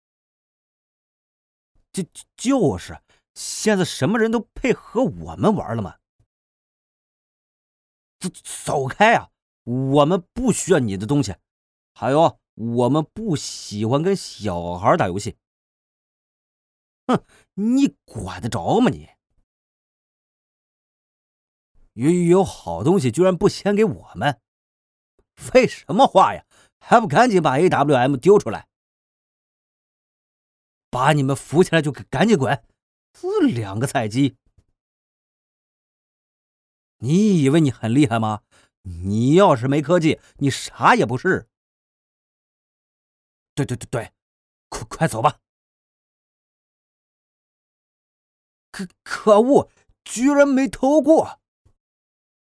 匹配队友（结巴）